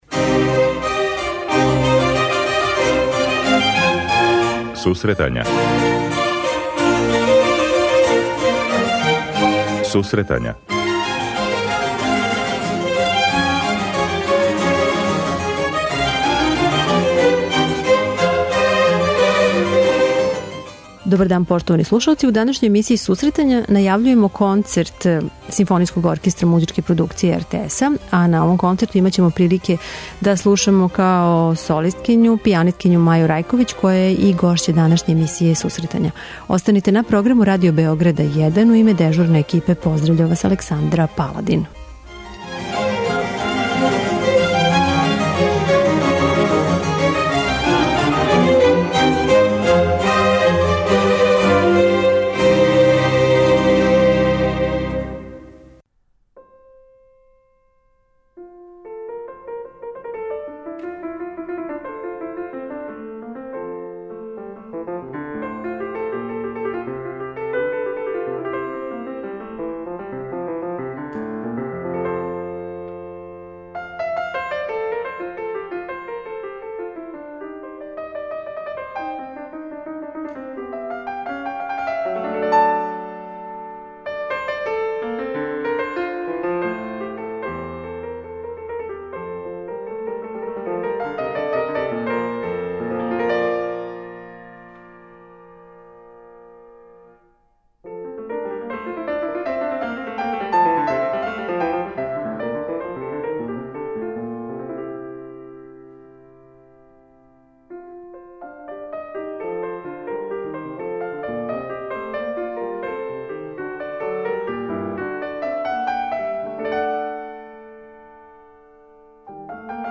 преузми : 26.22 MB Сусретања Autor: Музичка редакција Емисија за оне који воле уметничку музику.